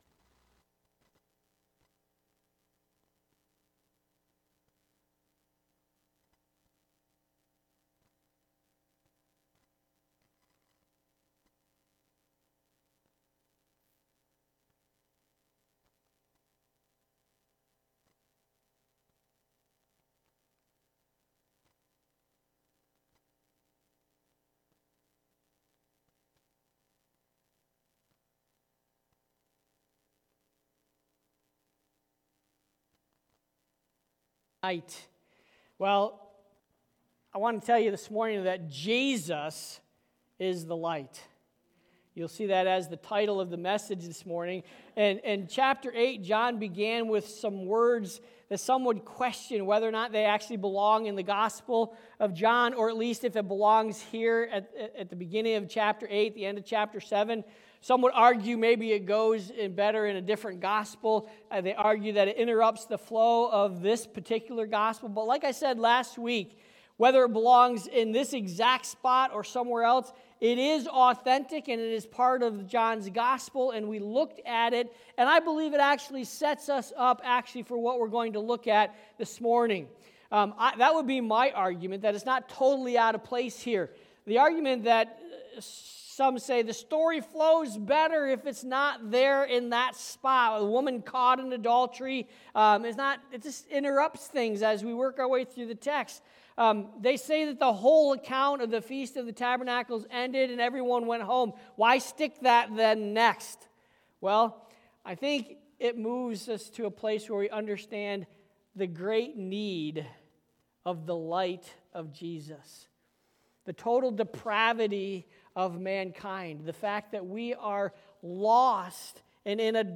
Sermons by CBCP